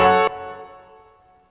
GUnit Keyz3.wav